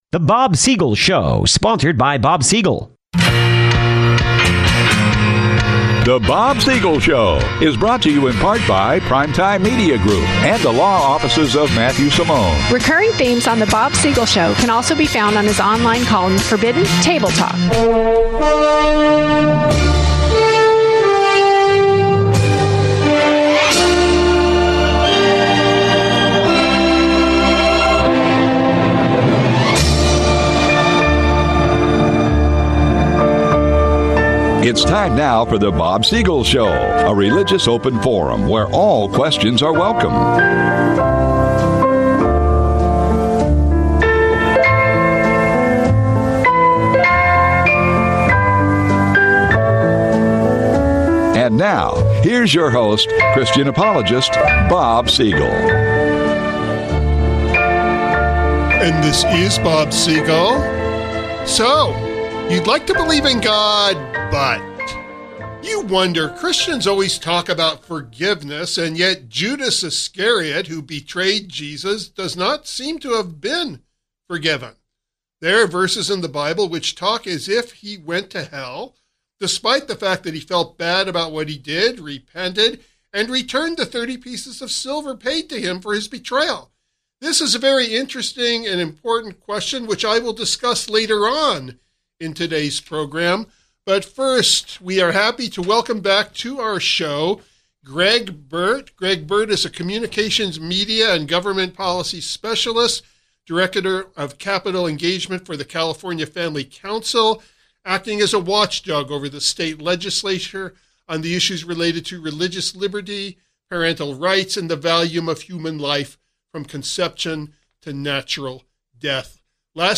(This interview airs without interruption.)